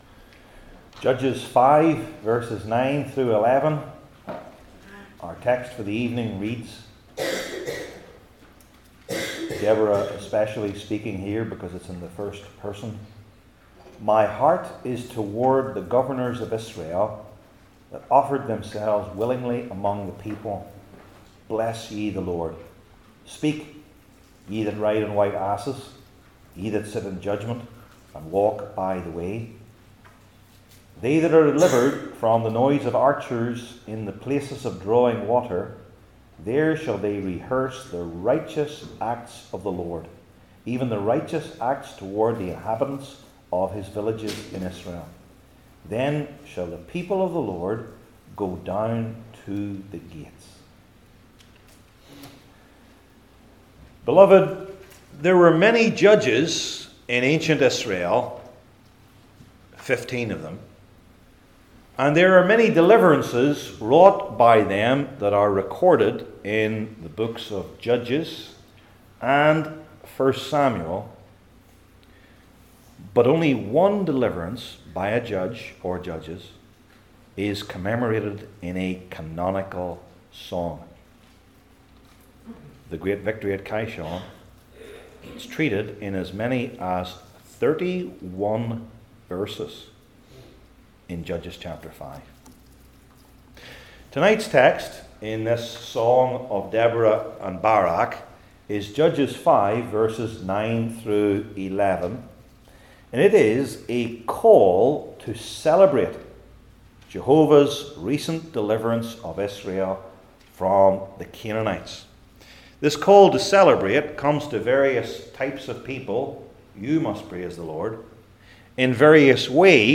Judges 5:9-11 Service Type: Old Testament Sermon Series I. The Governors That Volunteered II.